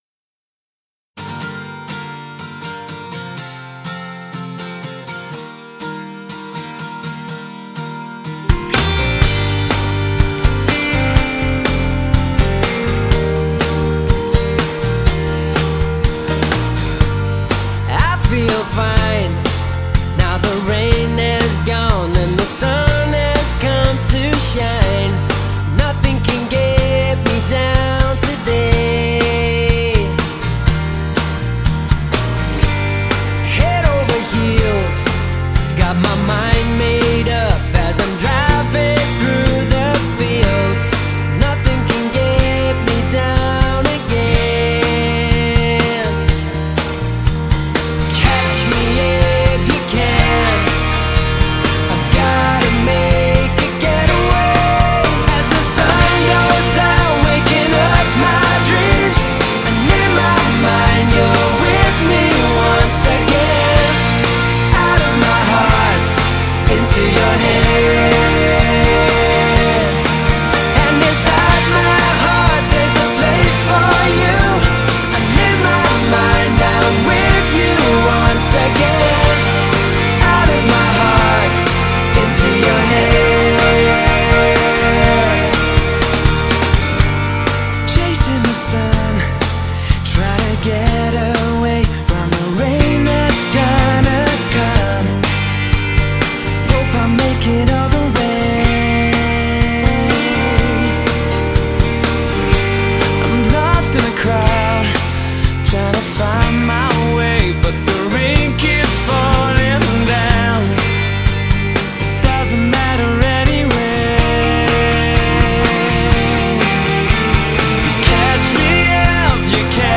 溶入更多Band-Sound 的感觉，并加入大量吉它声韵 与完美动人的合声编排
以流畅的吉他摇滚之编排，琅琅上口的副歌部分，使人一听就很容易的随之哼唱起来